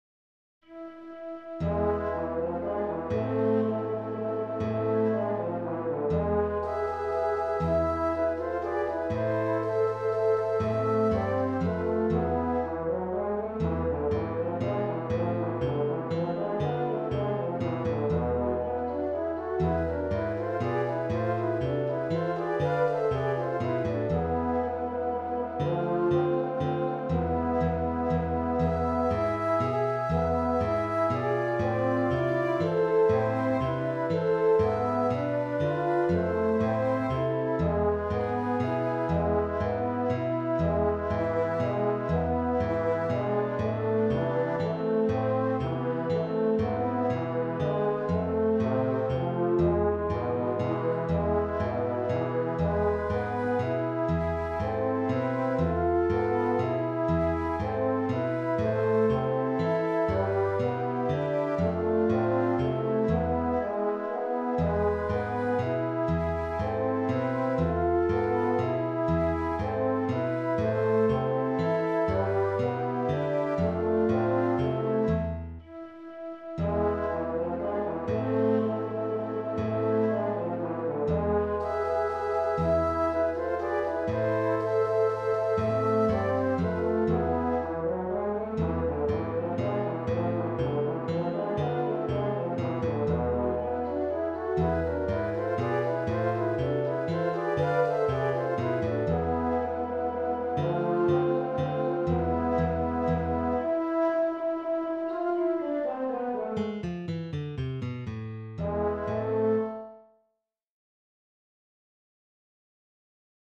Ensemble Series
for three guitars
This is a Spanish traditional.